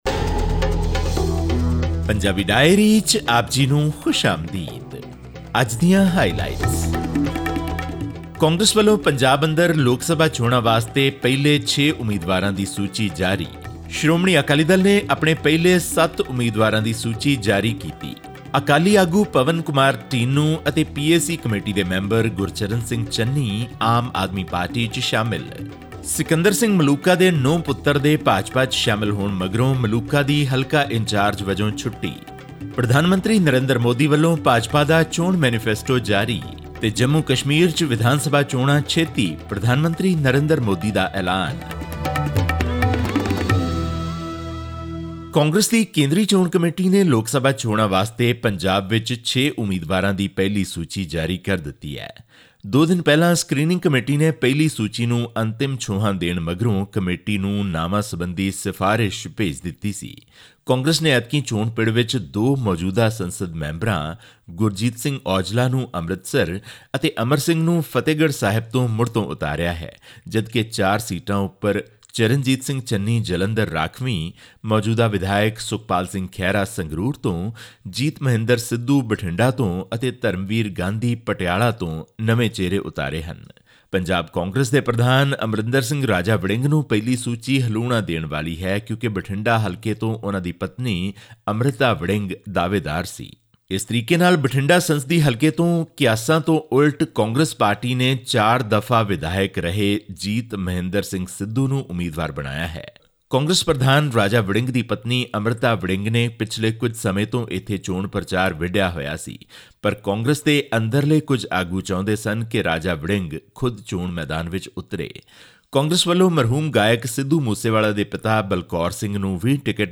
ਹੋਰ ਵੇਰਵੇ ਲਈ ਸੁਣੋ ਇਹ ਆਡੀਓ ਰਿਪੋਰਟ...